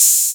808-OpenHiHats05.wav